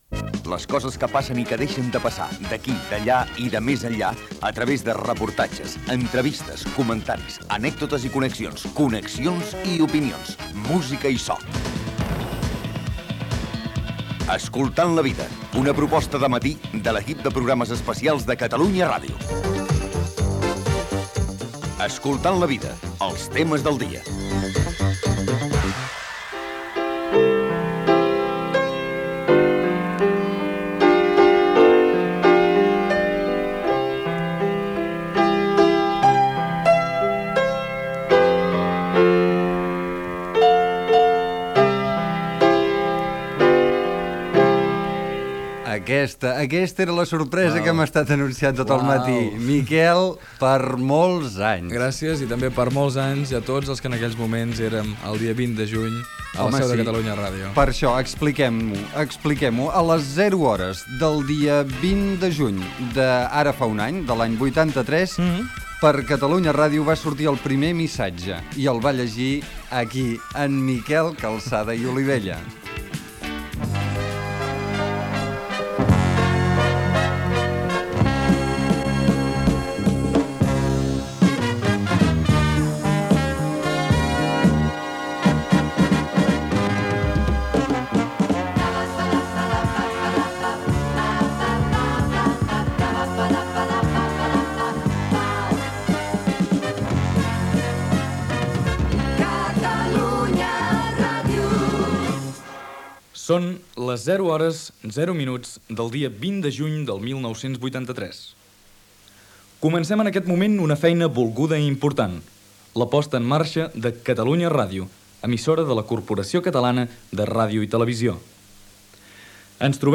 Indicatiu del programa, "Els temes del dia" dedicat a un any de l'inici de Catalunya Ràdio.
Entrevistes a Lluís Llach, que va composar la sintonia del període de proves.